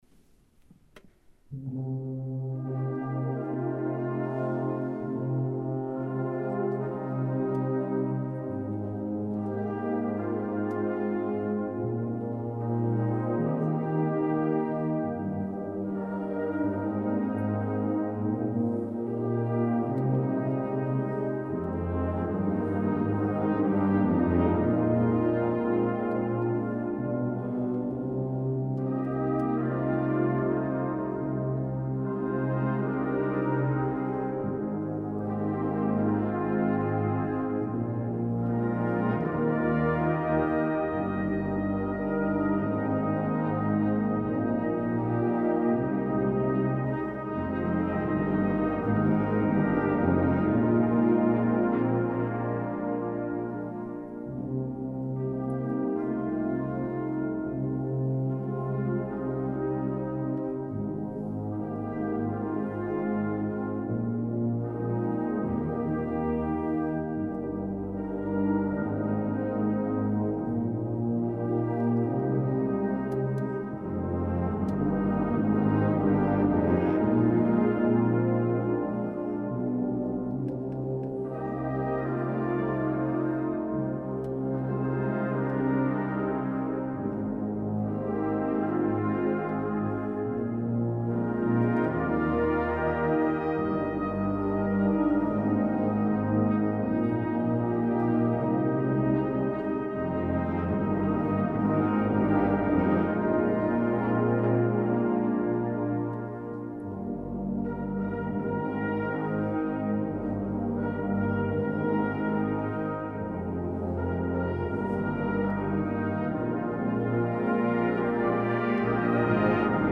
Warm, weit, strahlend – muss man selbst hören
Die Aufnahmen wurden von verschiedenen Ensembles aufgenommen und zur Verfügung gestellt.
Dieter Wendel, Ensemble der Posaunenwarte, Gloria 2024 S. 60